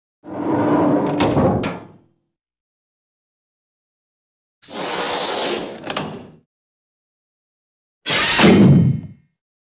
دانلود صدای در 6 از ساعد نیوز با لینک مستقیم و کیفیت بالا
جلوه های صوتی
برچسب: دانلود آهنگ های افکت صوتی اشیاء دانلود آلبوم صدای باز و بسته شدن درب از افکت صوتی اشیاء